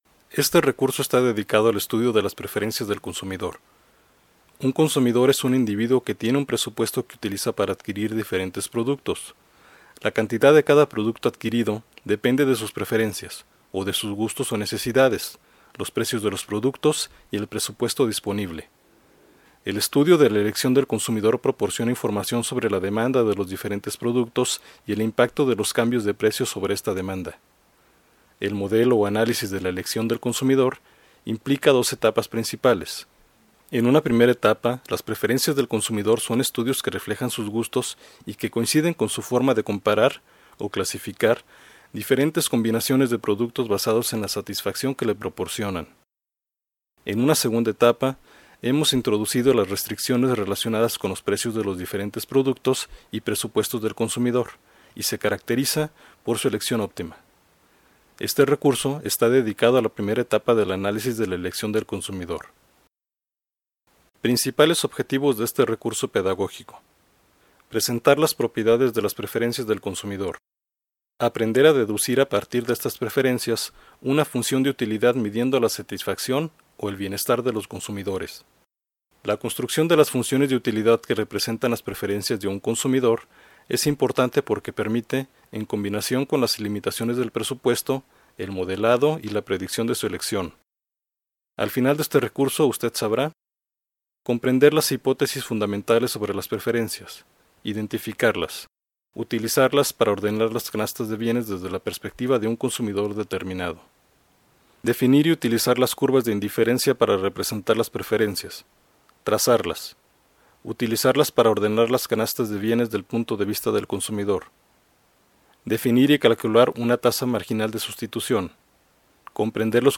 Introducción oral de las preferencias del consumidor